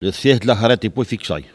Elle provient de Saint-Jean-de-Monts.
Catégorie Locution ( parler, expression, langue,... )